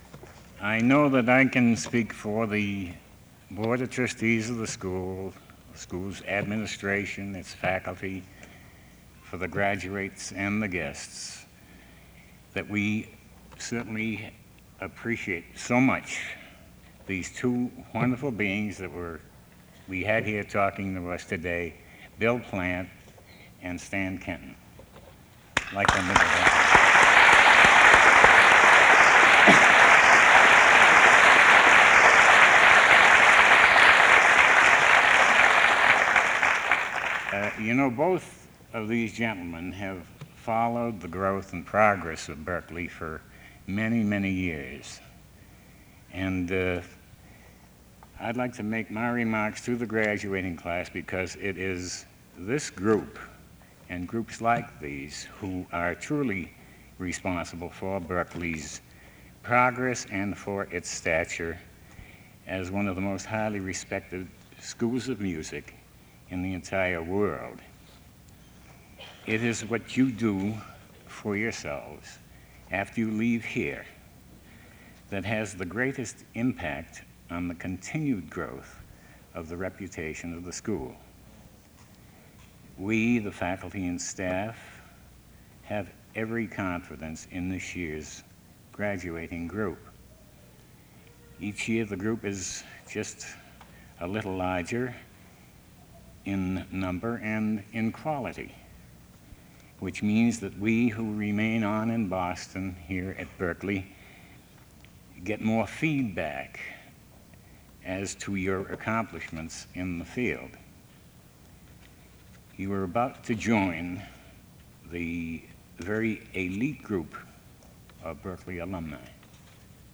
1969--Commencement--006 | Berklee Archives